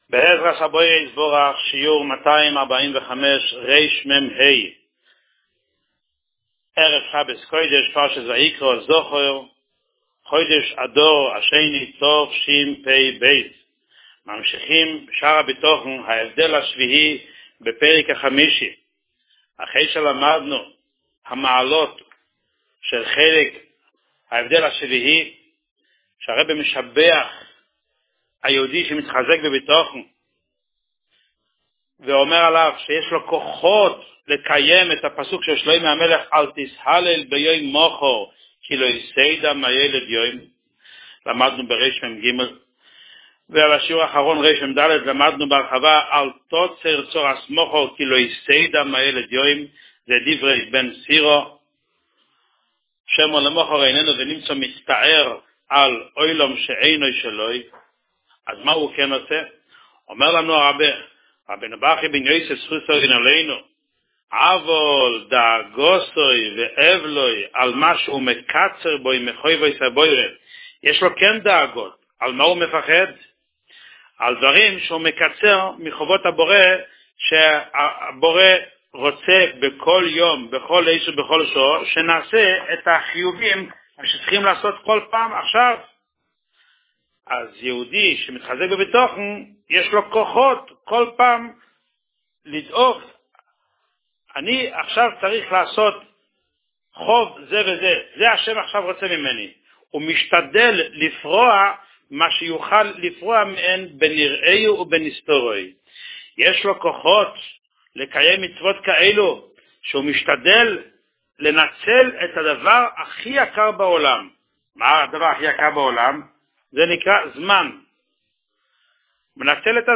שיעור מספר 245